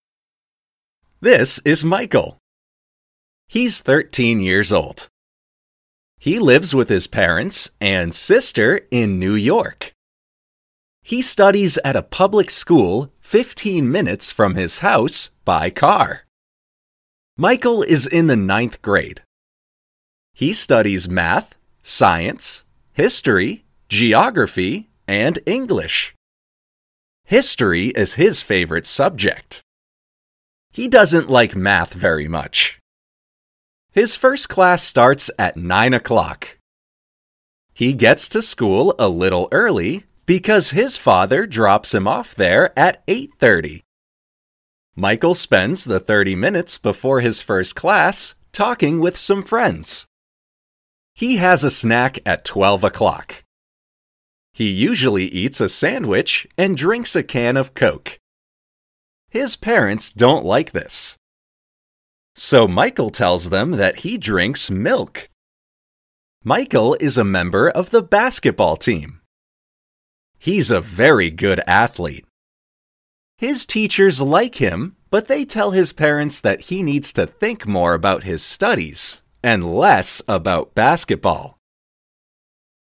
villa / IDIOMAS / Curso de inglês - Blue English - Blue English / 6.